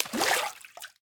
Minecraft Version Minecraft Version snapshot Latest Release | Latest Snapshot snapshot / assets / minecraft / sounds / block / cauldron / dye3.ogg Compare With Compare With Latest Release | Latest Snapshot
dye3.ogg